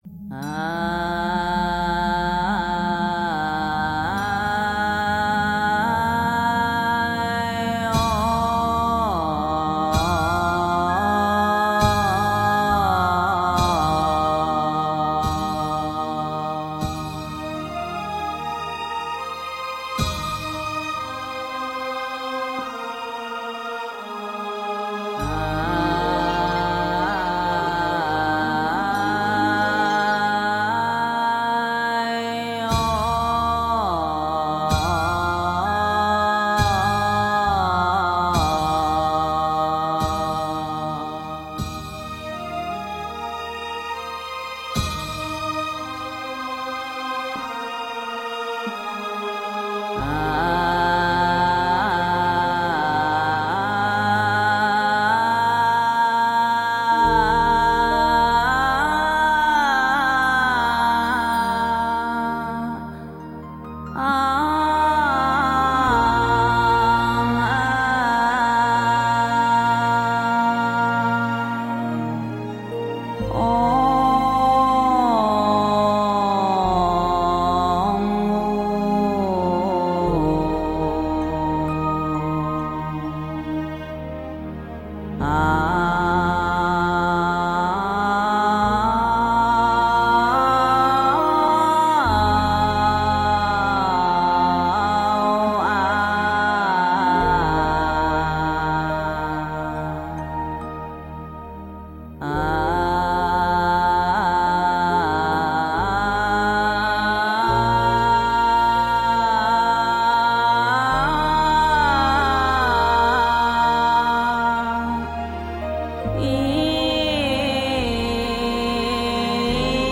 华严字母一合 诵经 华严字母一合--如是我闻 点我： 标签: 佛音 诵经 佛教音乐 返回列表 上一篇： 华严字母三合 下一篇： 南无大悲观世音菩萨三称 相关文章 南无阿弥陀佛 南无阿弥陀佛--海涛法师...